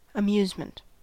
Ääntäminen
US : IPA : [ə.ˈmju.zmənt]